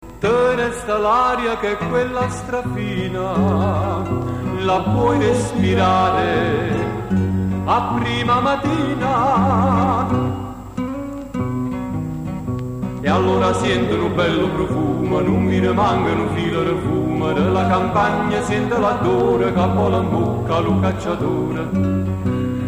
la seconda voce in controcanto
ballata_controcanto.mp3